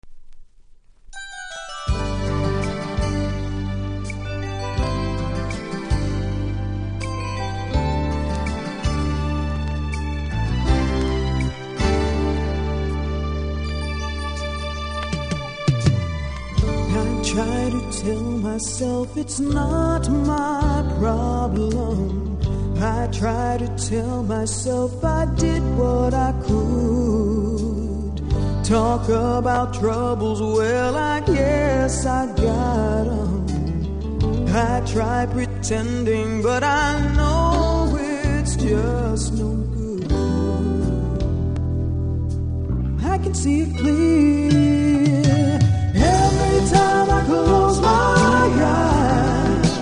序盤少しノイズありますので試聴で確認下さい、その分値段下げての出品です。